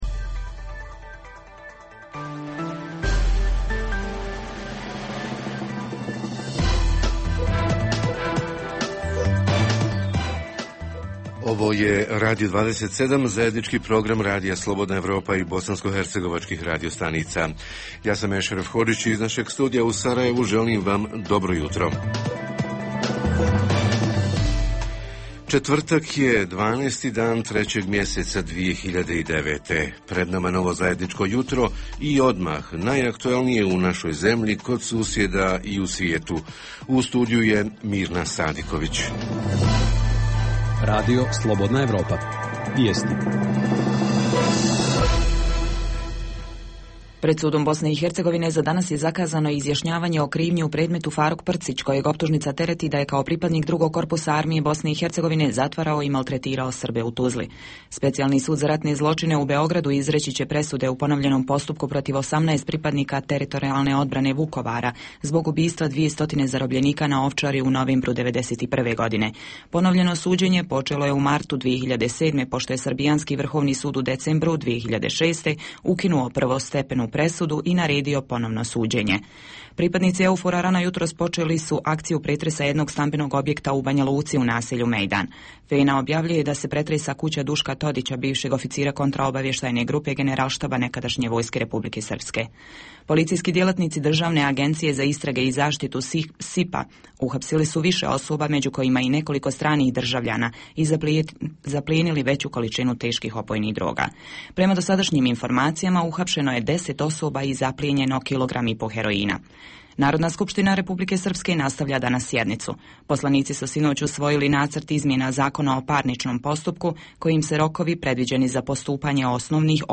Jutarnji program za BiH koji se emituje uživo istražuje: Lokalne vlasti i recesija /ekonomska kriza/ - kako smanjiti javnu potrošnju? Redovna rubrika Radija 27 četvrtkom je “Radio ordinacija”. Redovni sadržaji jutarnjeg programa za BiH su i vijesti i muzika.